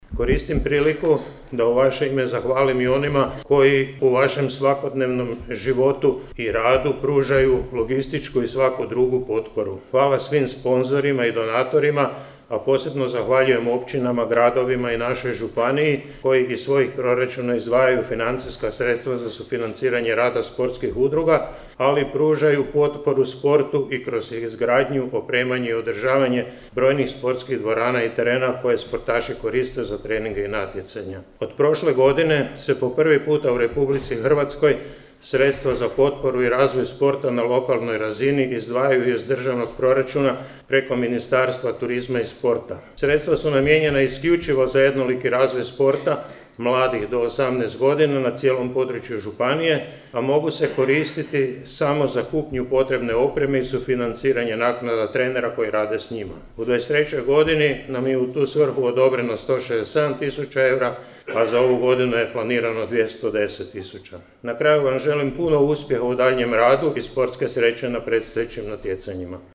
U koprivničkom Domu mladih održano je proglašenje najuspješnijih sportašica, sportaša i sportskih ekipa Koprivničko-križevačke županije u 2023. godini, u organizaciji Zajednice sportova Koprivničko-križevačke županije.